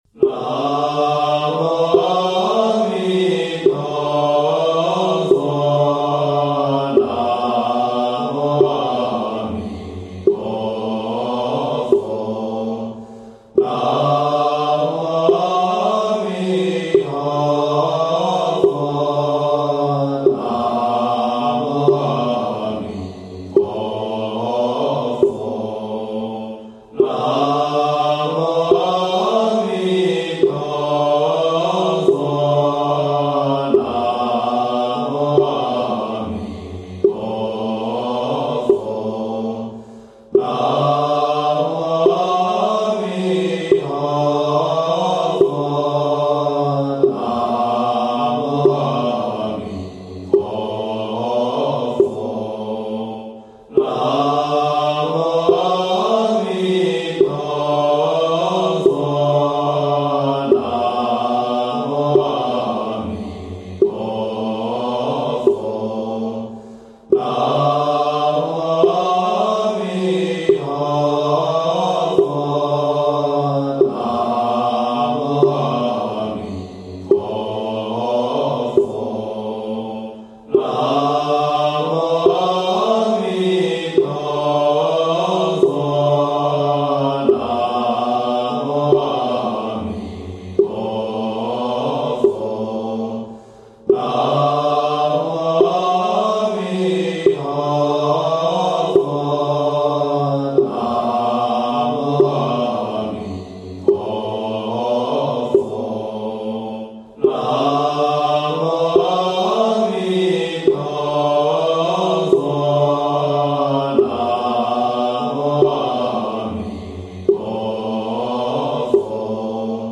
108声阿弥陀佛佛号 文本助手 资讯搜索 分享好友 打印本文 关闭窗口 阅读关键词 佛教资讯